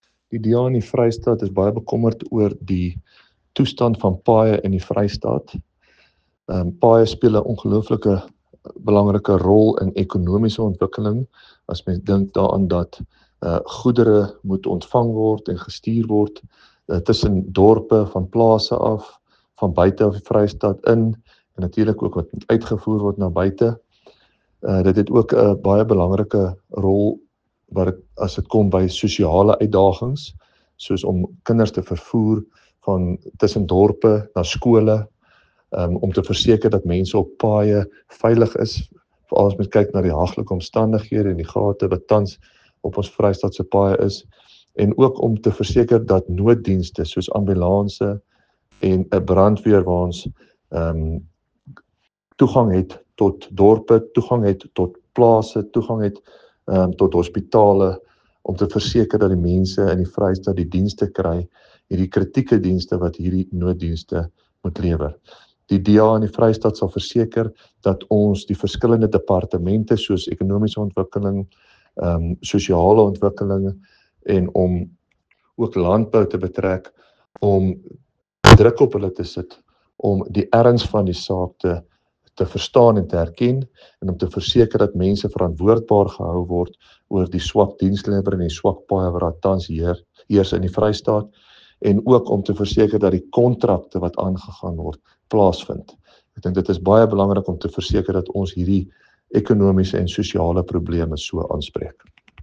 Afrikaans soundbites by David van Vuuren MPL and